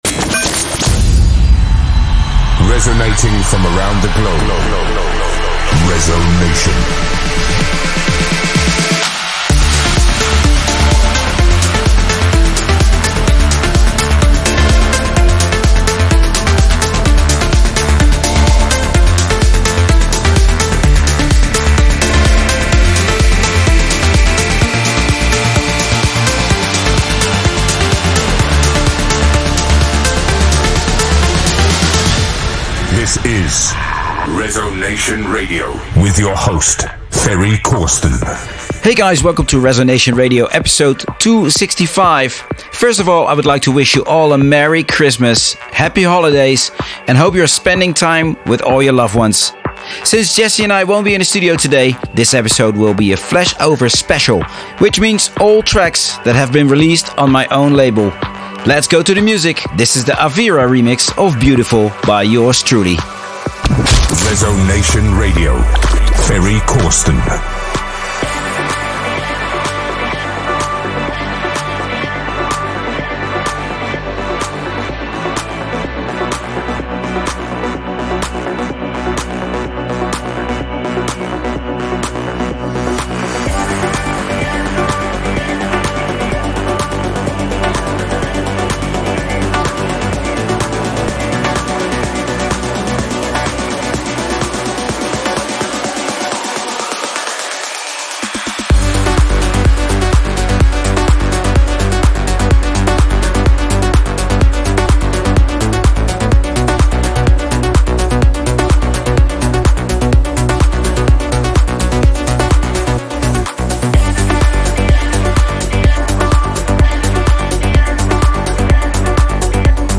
music DJ Mix in MP3 format
Genre: Trance